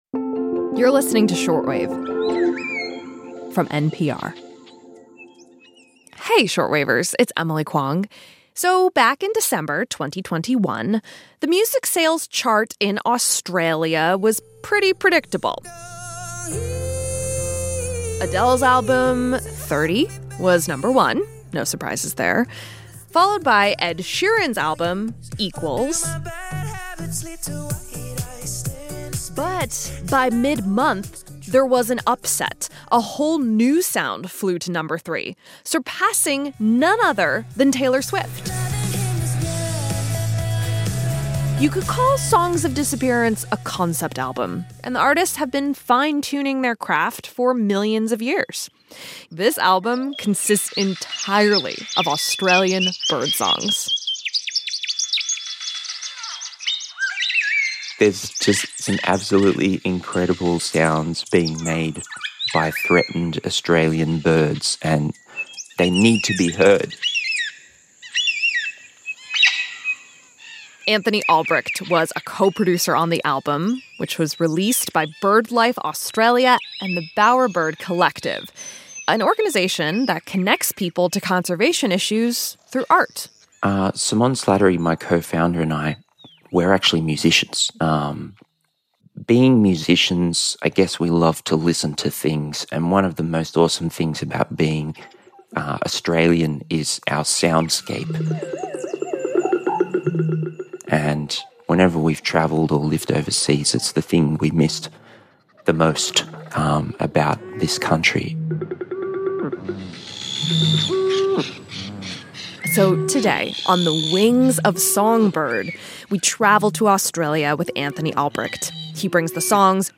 What bird has a ten-foot wingspan and breeds almost exclusively on a single island in the Pacific Ocean? Find out in this special quiz episode of Short Wave.